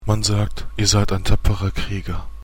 Deutsche Sprecher (m)